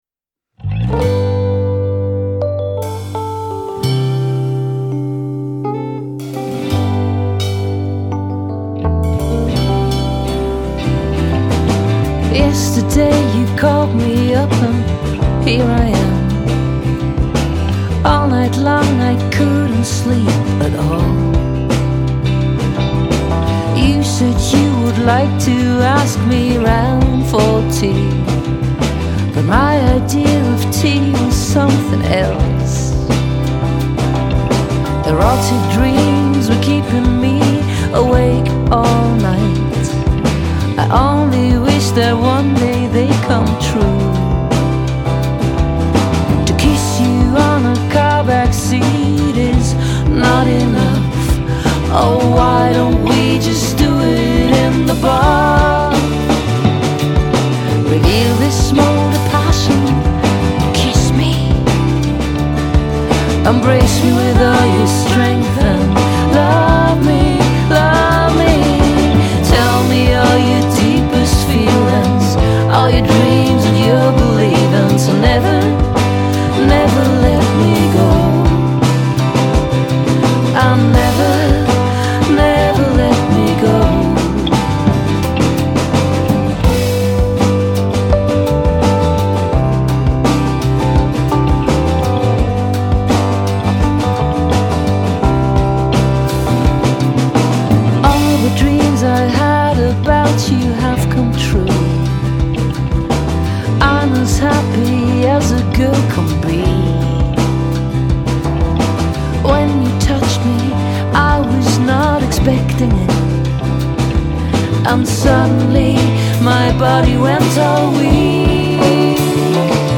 Bass
Drums
Gitarre, Gitarren-Arrangements
E-Gitarre
Akkordeon, Posaune, Euphonium, Trompete, Tuba
Gitarre, Tres
Violine
Kontrabass
Bansuri
Slide-Gitarre, Dobro, E-Gitarre
Vibraphone, Hackbrett
Fender Rhodes
Gitarre, Pedal Steel Guitar